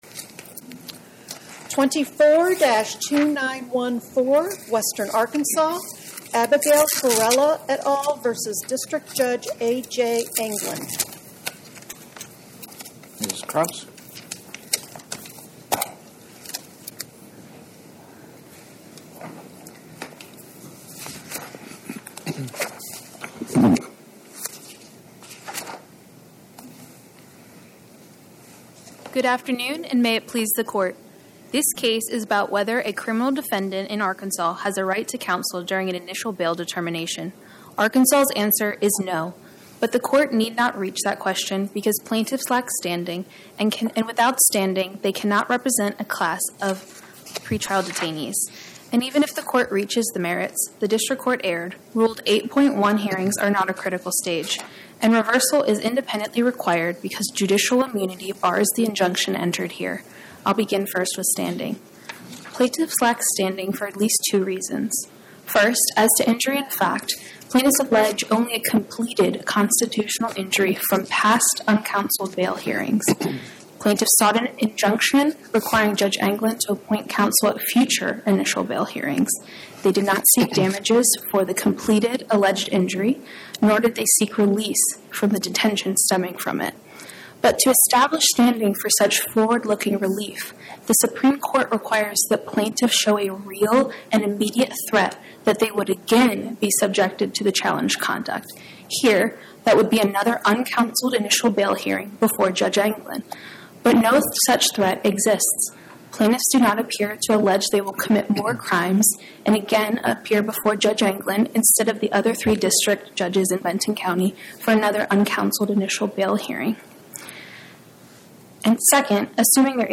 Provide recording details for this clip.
Oral argument argued before the Eighth Circuit U.S. Court of Appeals on or about 01/15/2026